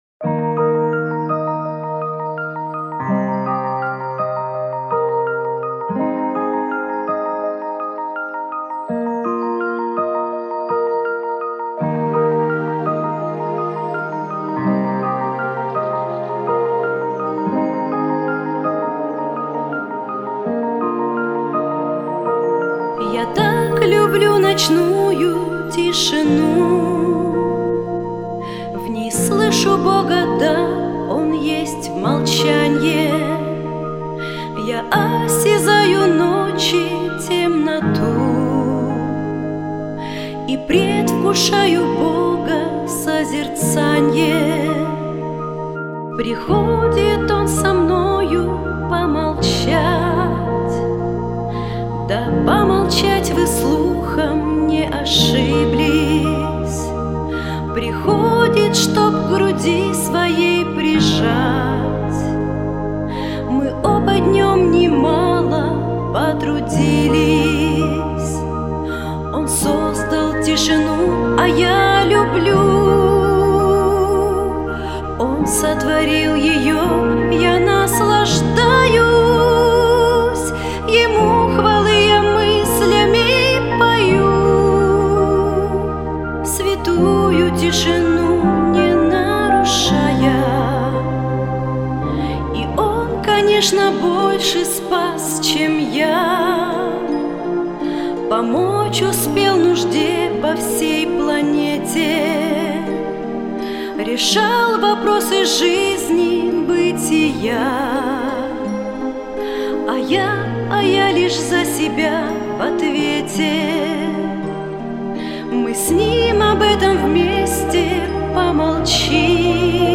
194 просмотра 284 прослушивания 23 скачивания BPM: 75